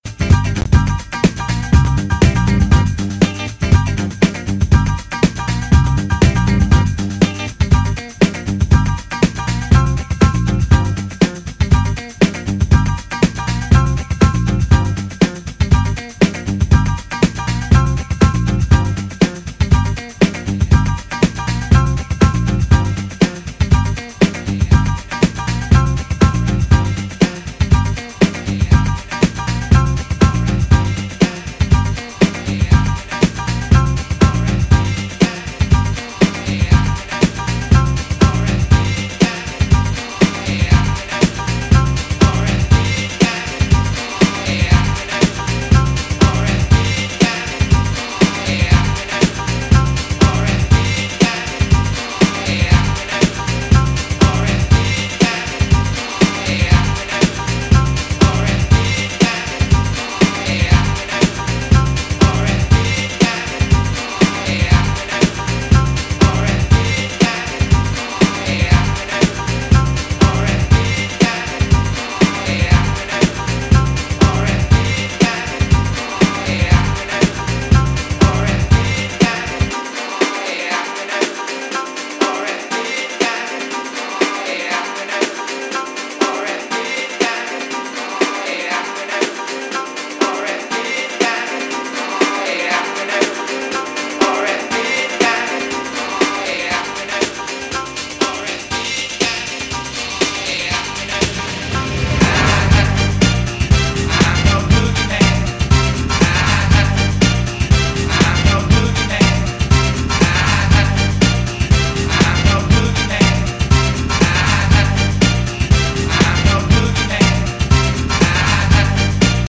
微妙にチープ＆安っぽい雰囲気がむしろたまらない、クセになる出来！